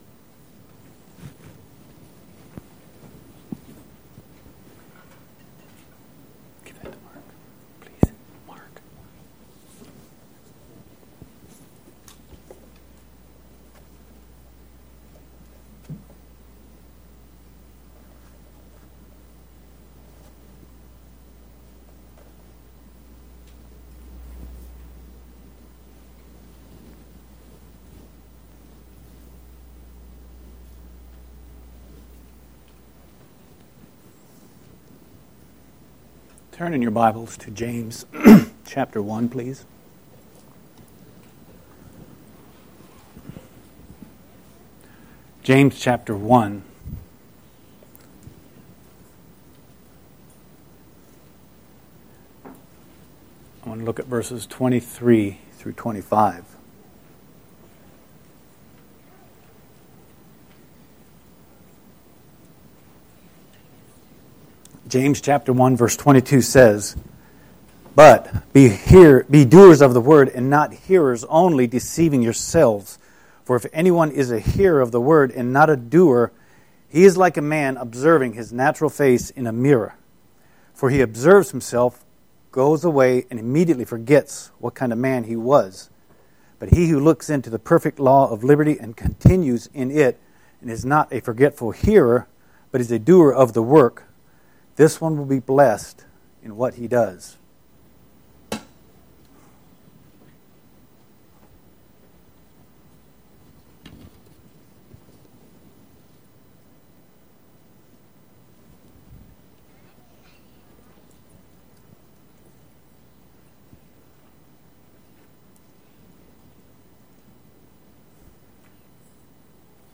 Sermons – Page 3 – Shiloh Mennonite